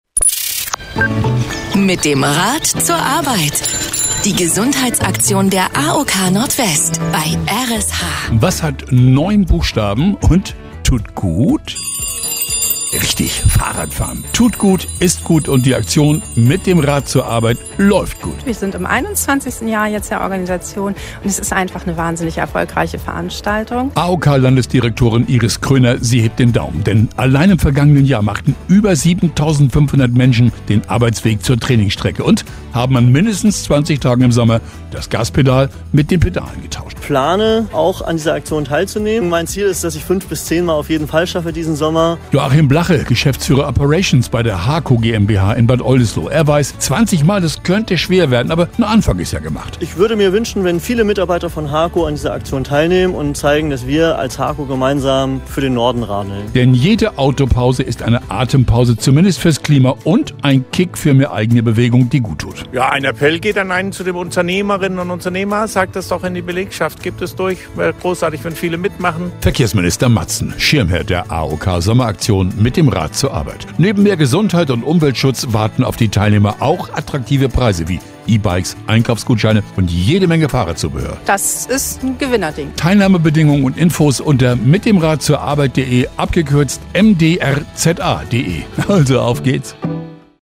R.SH-Sendebeitrag 1